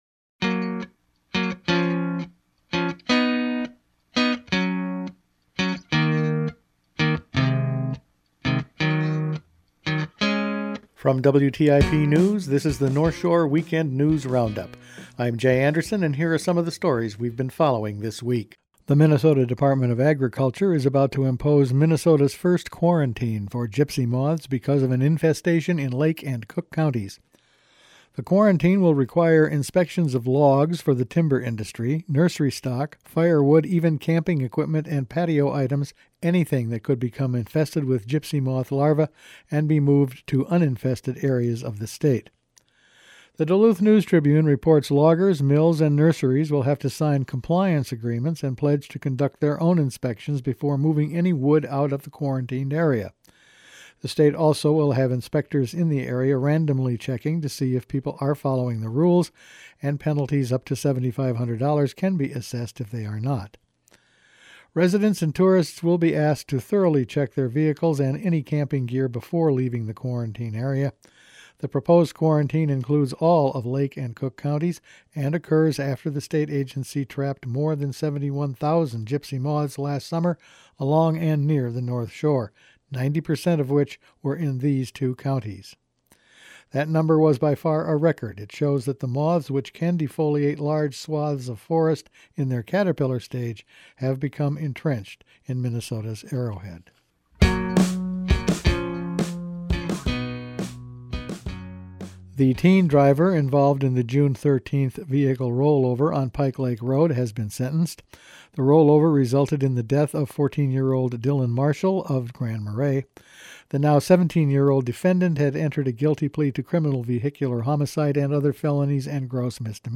Weekend News Roundup for January 25
Each week the WTIP news staff puts together a roundup of the news over the past five days. Gypsy moths, mining on the range and cold war era munitions…all in this week’s news.